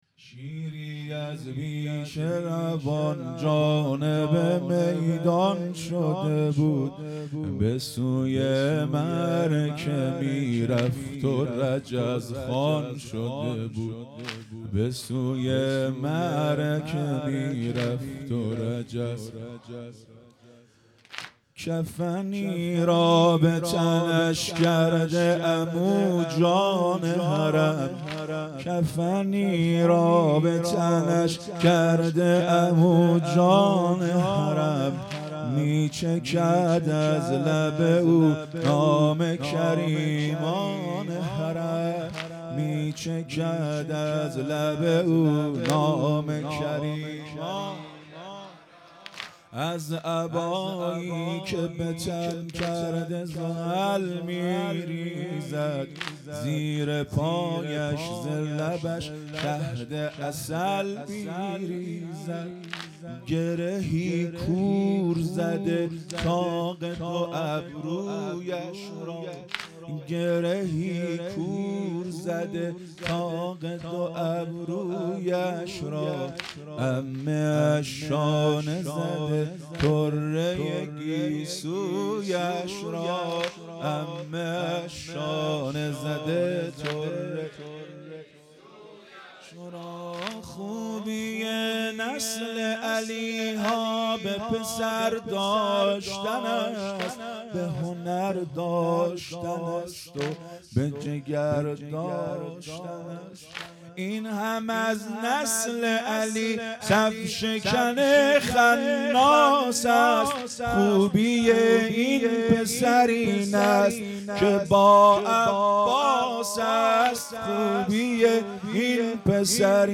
هیئت مکتب الزهرا(س)دارالعباده یزد
محرم ۱۴۴۵_شب ششم